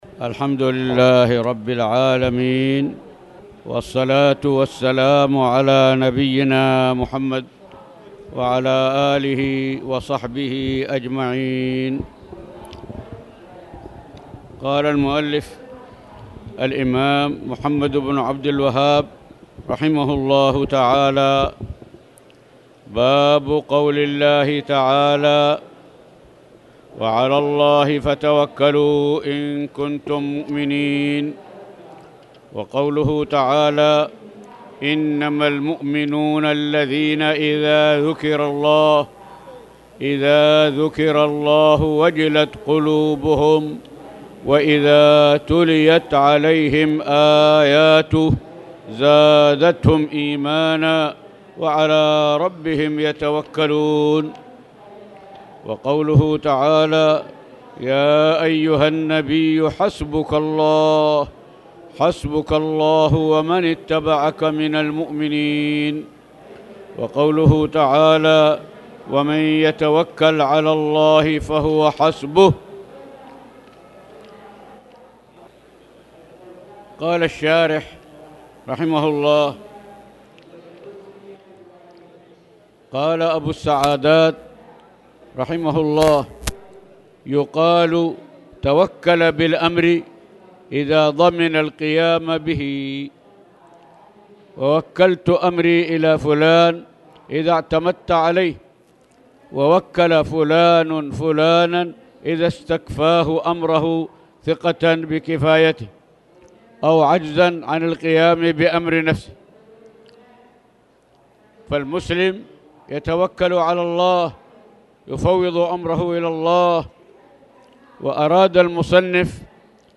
تاريخ النشر ٢٧ صفر ١٤٣٨ هـ المكان: المسجد الحرام الشيخ